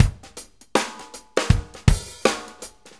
Shuffle Loop 23-02.wav